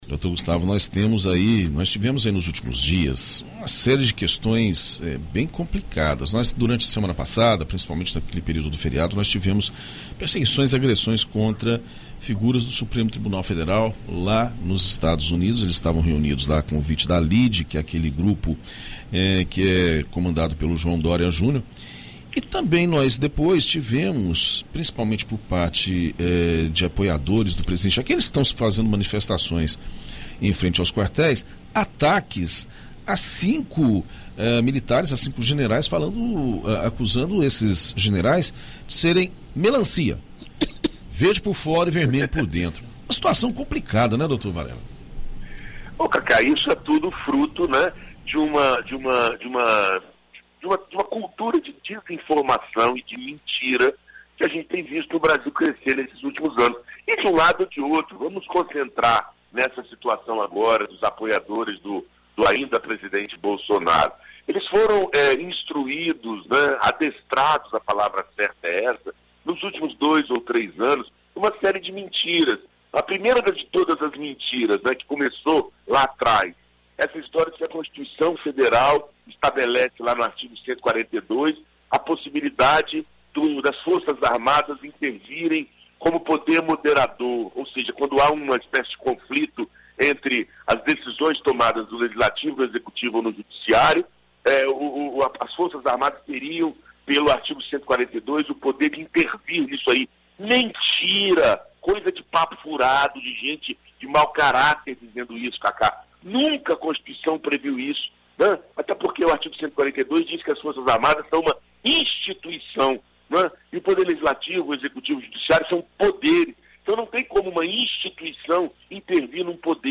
Na coluna Direito para Todos desta segunda-feira (21), na BandNews FM Espírito Santo